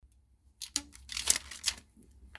Звук перетягивания купюр резинкой
Характерные звуки скрепления денег
zvuk-rezinki.mp3